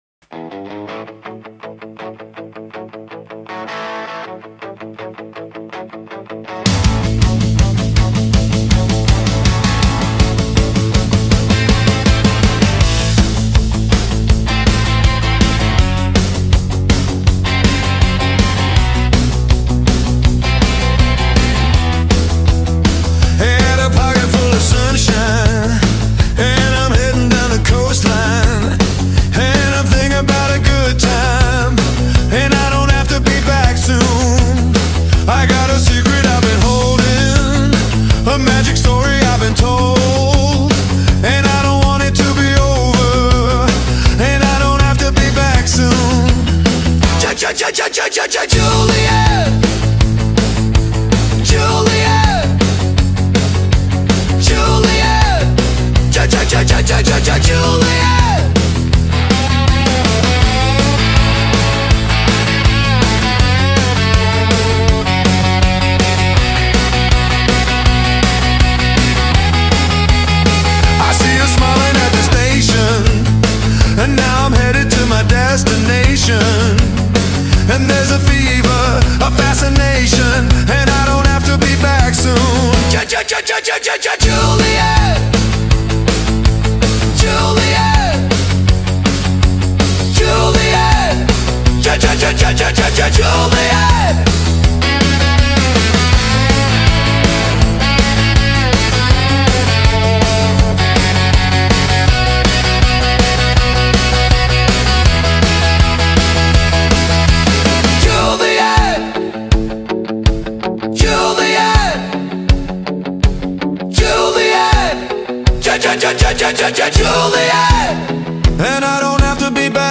una vibrante canción de rock and roll retro de los 70